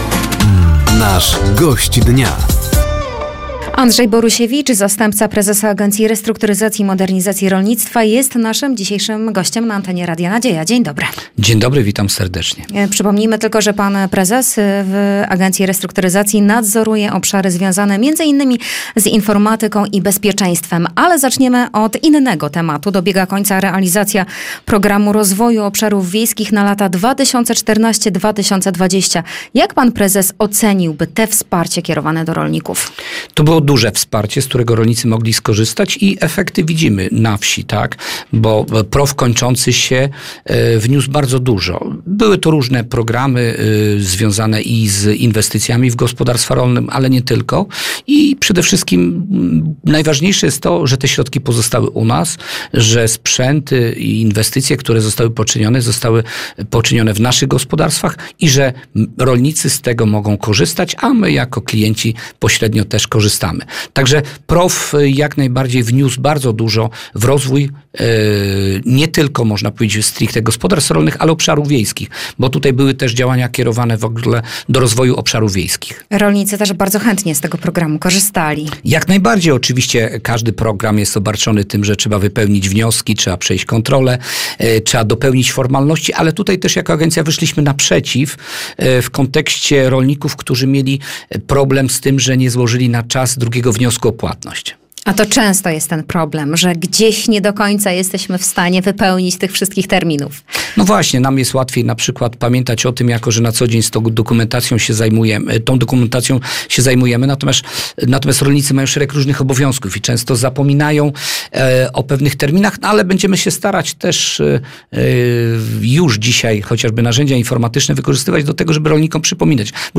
Zapraszamy do wysłuchania rozmowy z Andrzejem Borusiewiczem, zastępcą Prezesa Agencji Restrukturyzacji i Modernizacji Rolnictwa.